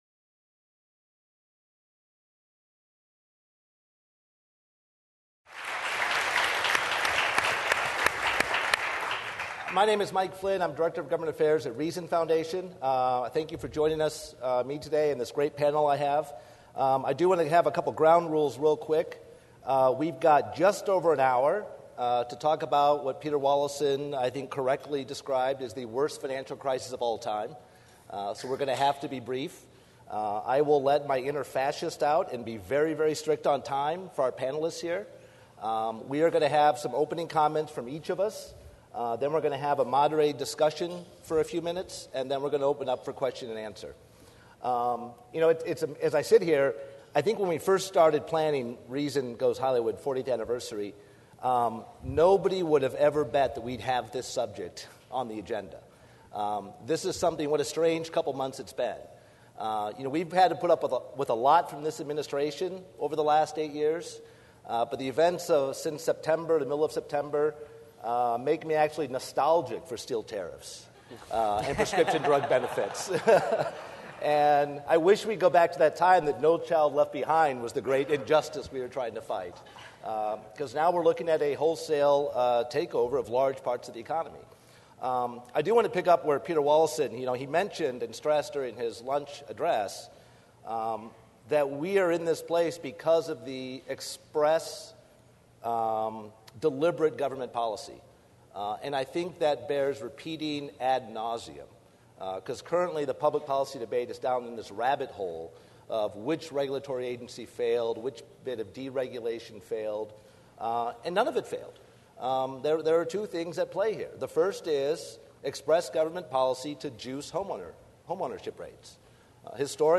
At Reason Goes Hollywood, our 40th anniversary bash held November 14-15 in Los Angeles, no topic generated more heat than the financial crisis and the government's seemingly endless (and endlessly revised) bailout plans.